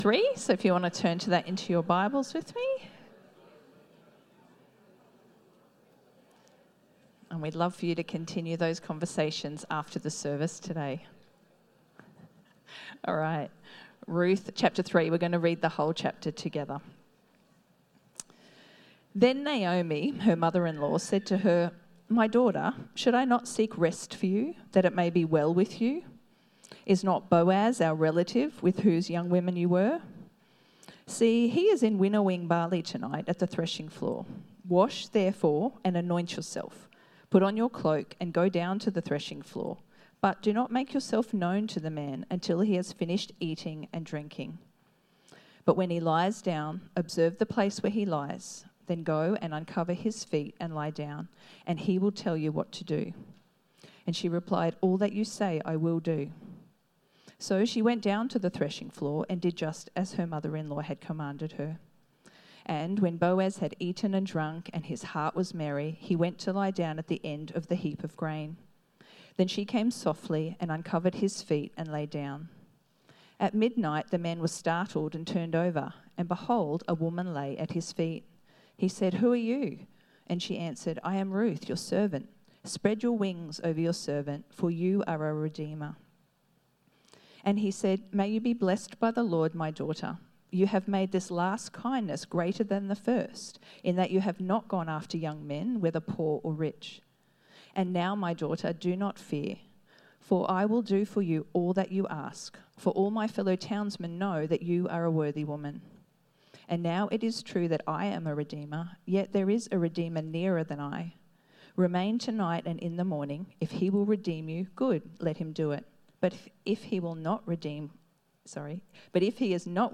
Sermons | Coomera Baptist Church